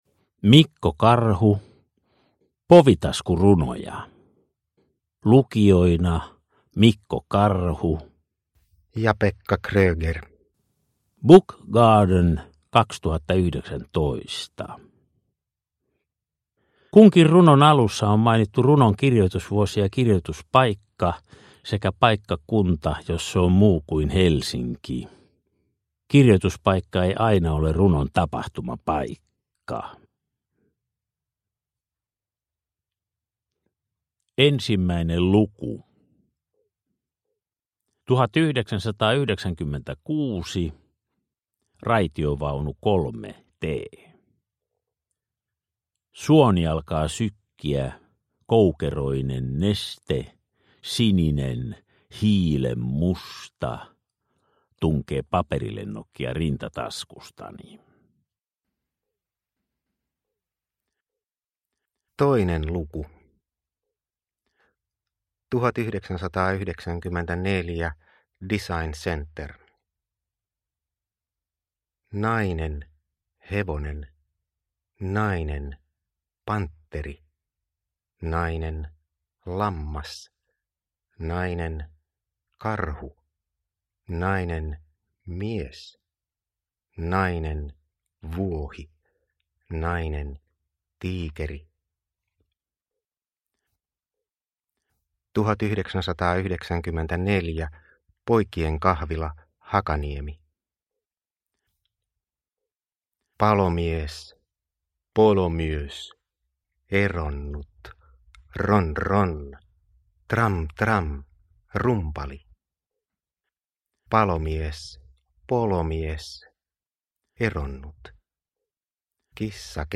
Povitaskurunoja – Ljudbok
Lyrik Njut av en bra bok